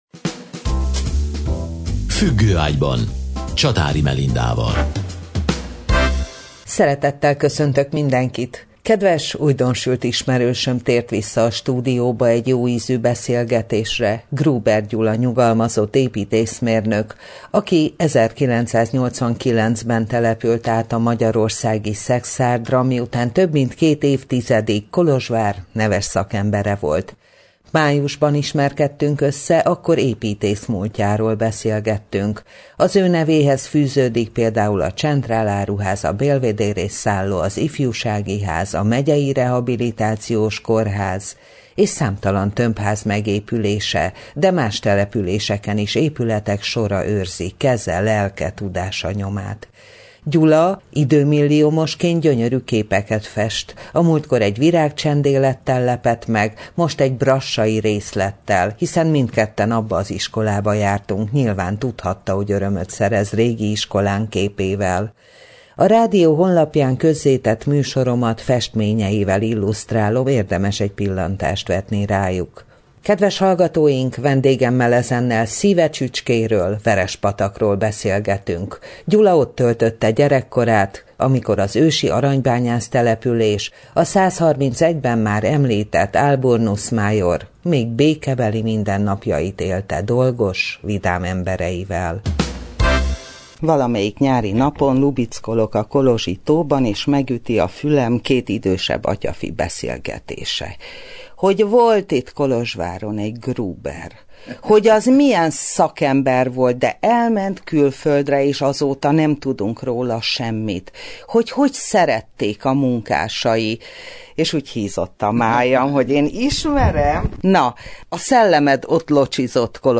Vendégünkkel szíve csücskéről beszélgetünk, az ősi aranybányász településről, a 131-ben már említett Alburnus Maior-ról.